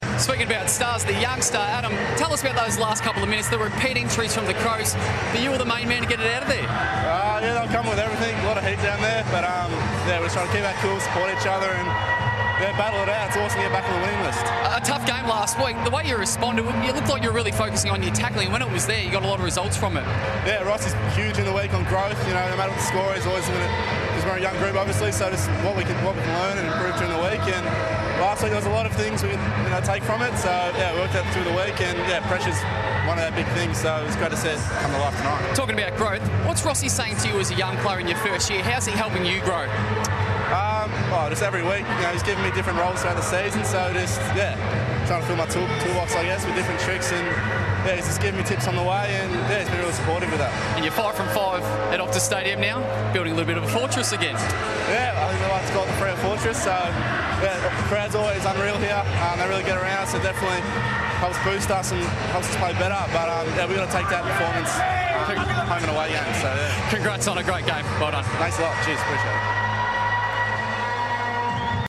Adam Cerra spoke to FOX Footy following the win over Adelaide.